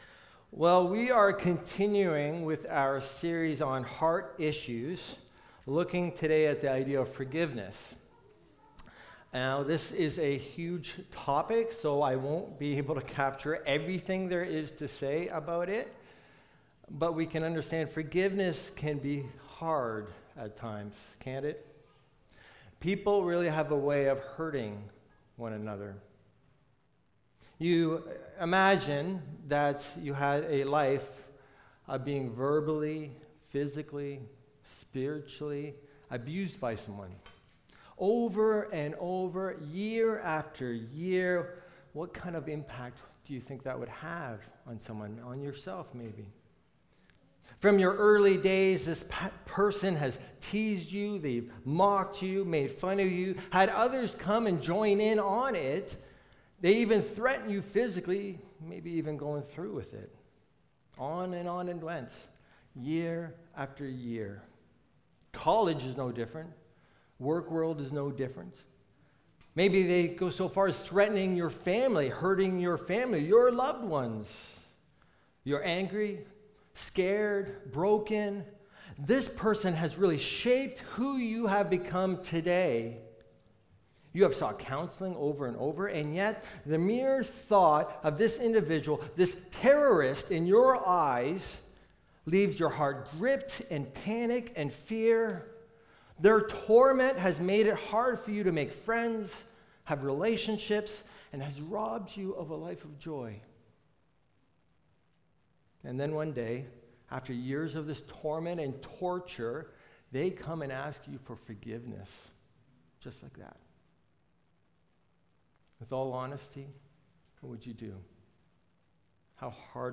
We are called to forgive as the Lord has forgiven us. This sermon challenges us to reflect Christ in our relationships, pursue peace in the body, and let the Word of Christ dwell richly in us.